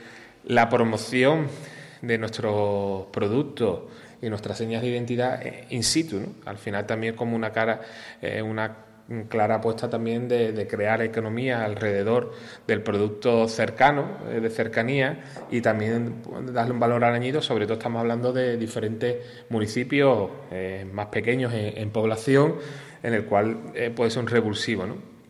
Corte de Jaime Armario